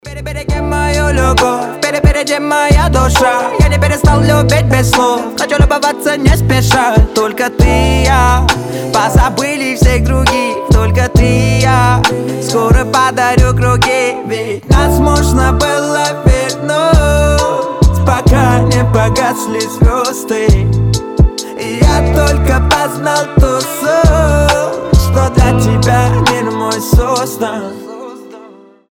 лирика
медленные